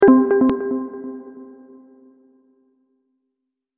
دانلود صدای اعلان خطر 12 از ساعد نیوز با لینک مستقیم و کیفیت بالا
جلوه های صوتی